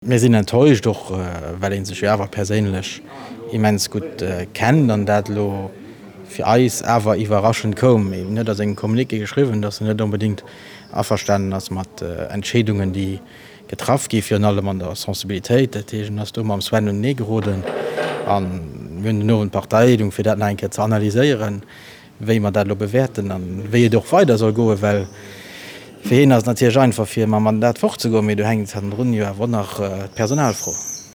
Dem Marc Goergen seng Reaktioun